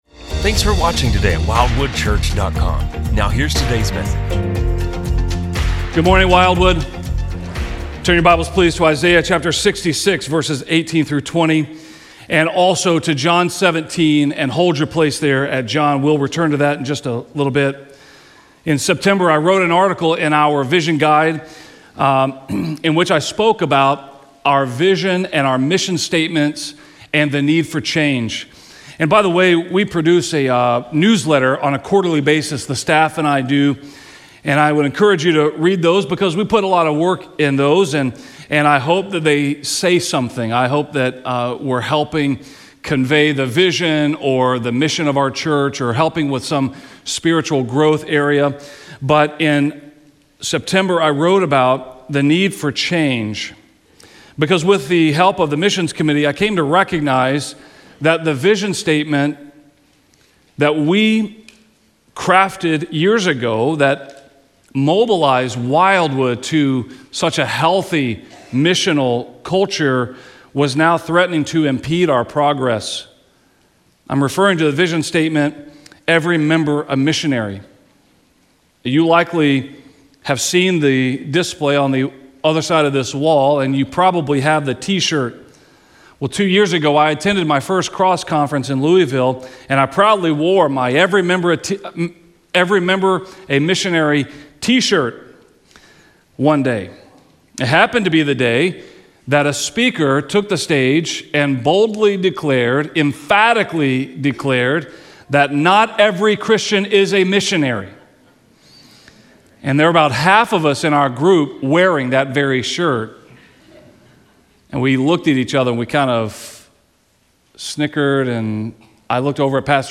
This sermon invites our church family to unite around a vision that has compelled God’s people for millennia and will culminate in the worship of Christ by all peoples.